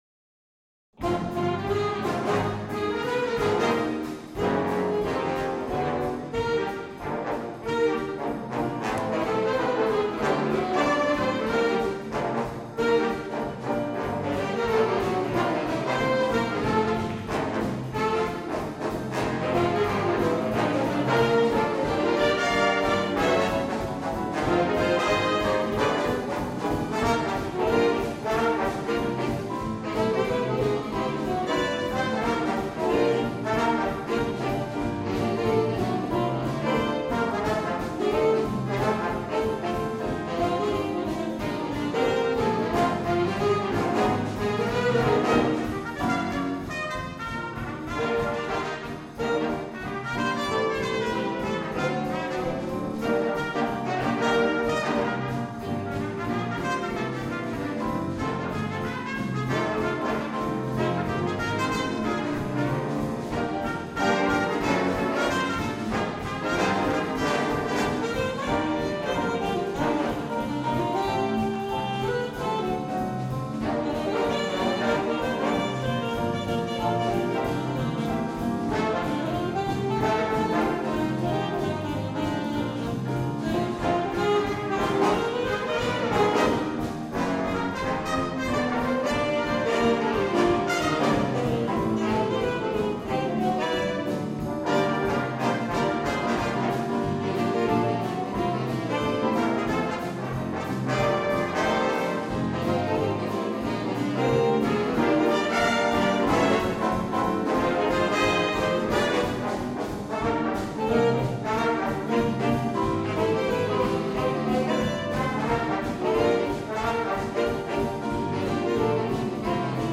Swing, Funk & Jazz aus Obertshausen
Erleben Sie uns live